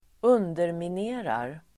Ladda ner uttalet
Uttal: [²'un:dermine:rar]